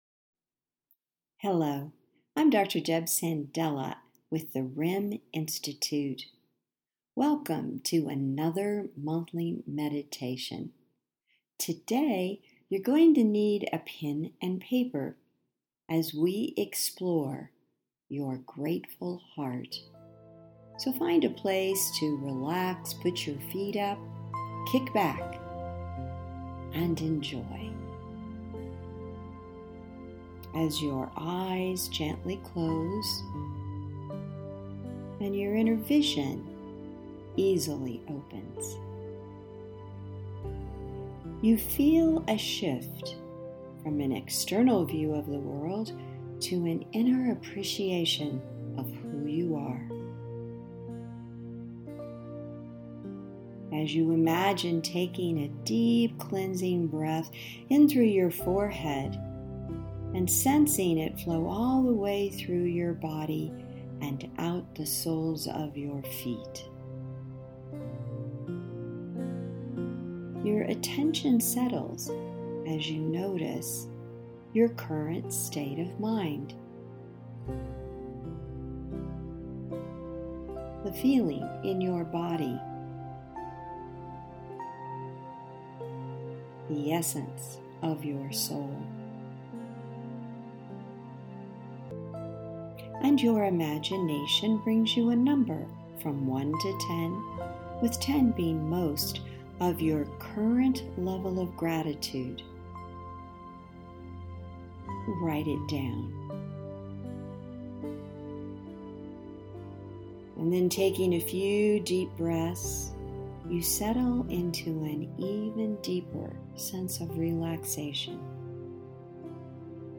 Experience The Benefits Of Gratitude With This Audio Guided RIM Experience:
July-15-Meditation.mp3